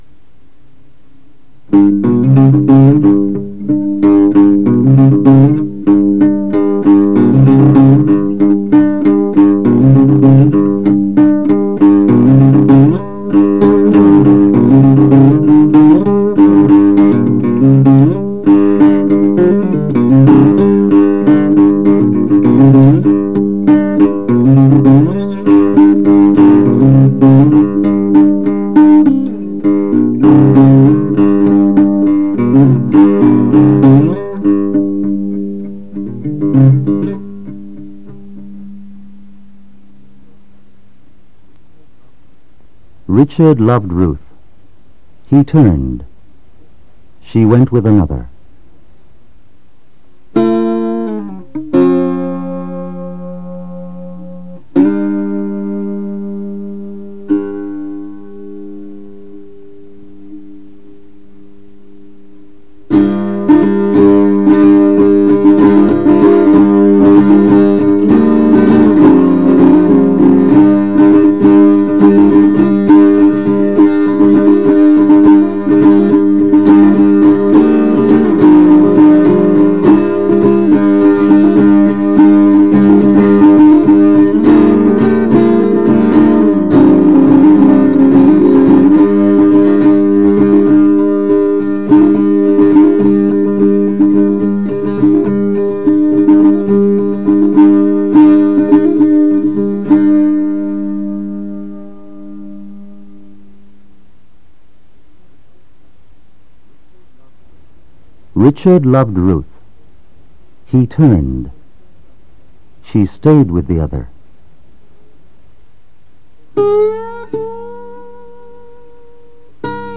Why? audio: voice & music APPROACH EXPLORATION THEMES ILLUSTRATION ( THE TRIAL )